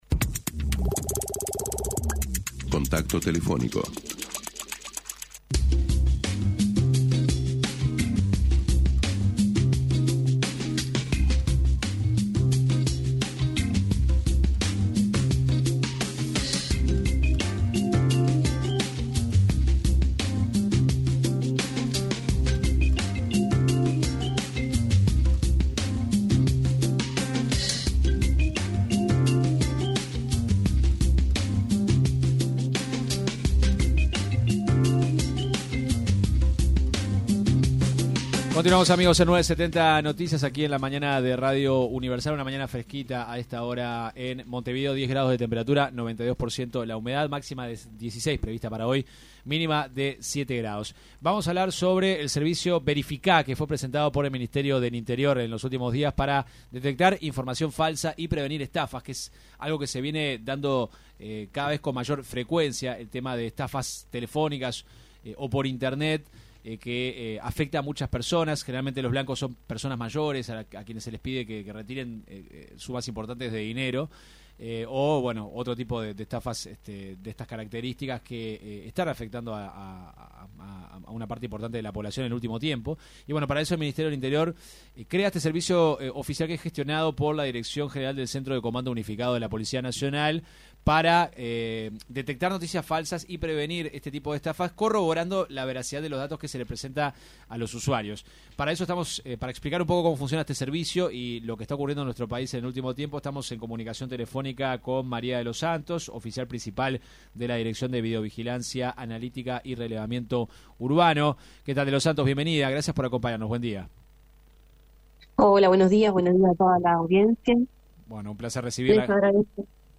En diálogo con 970 Noticias